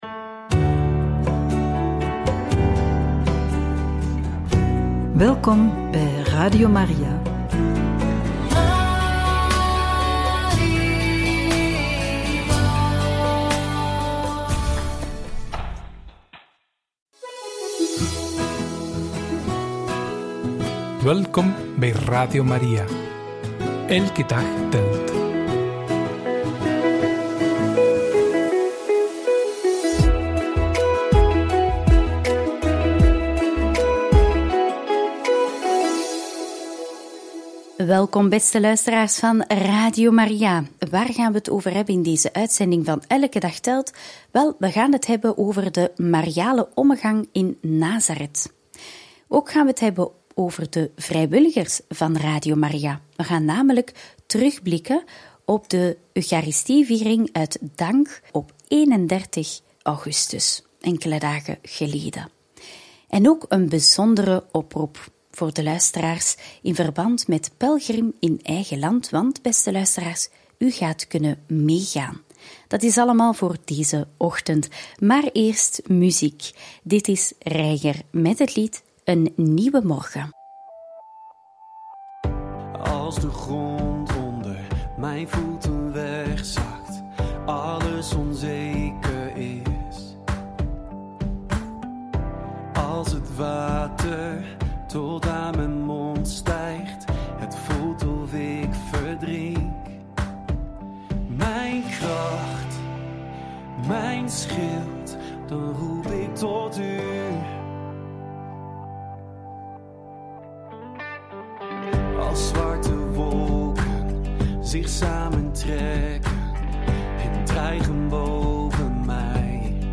Over de Mariale Ommegang in Nazareth, homilie van de dankviering voor de vrijwilligers en oproep: wordt u pelgrim met Radio Maria?